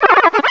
pokeemerald / sound / direct_sound_samples / cries / turtwig.aif